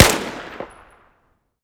m4a1_2.ogg